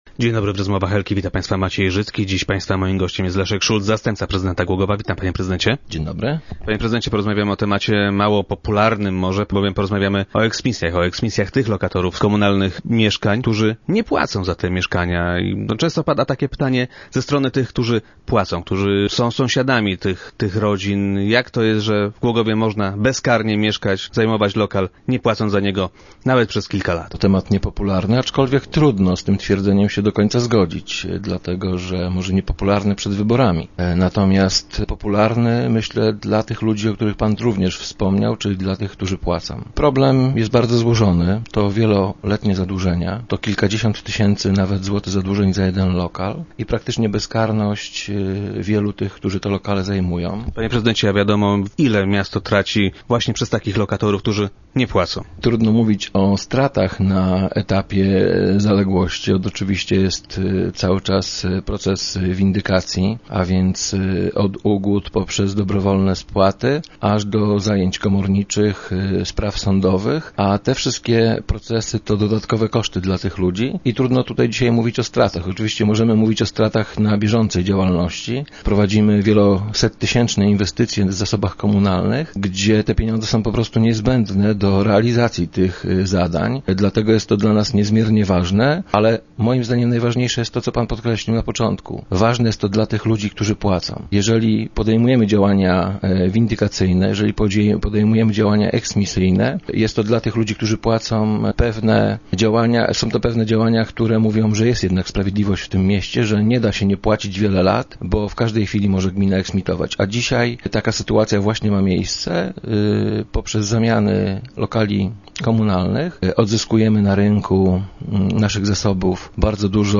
Start arrow Rozmowy Elki arrow Szulc: Niezbędna jest dobra wola dłużnika
Jak zapewnia Leszek Szulc, zastępca prezydenta Głogowa, na wsparcie gminy mogą jednak liczyć ci, których zaległości nie są jeszcze zbyt duże.